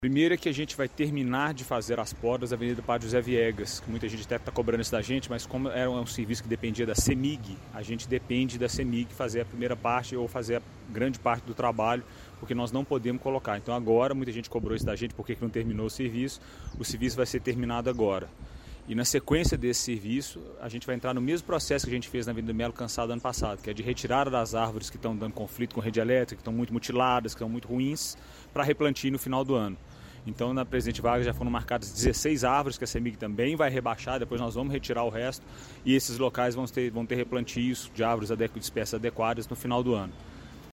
Até o final de abril os trabalhadores esperam terminar a poda das árvores da Avenida Padre José Viegas. Em seguida, na Avenida Presidente Vargas, como conta o secretário José Hermano de Oliveira Franco: